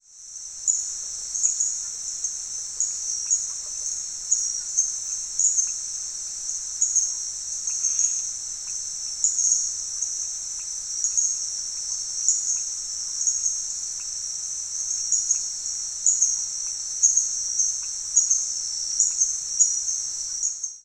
In January 2025, I was trying to record some Red-webbed Treefrogs and Harlequin Treefrogs around the pond on our hotel grounds.
The call of this species is a high pitched peep or tick with a dominant frequency ~6600 Hz.
(This recording is filtered and edited to remove some of the other distracting species of frogs heard calling.)
Yellow-flecked Glassfrog calls from Panama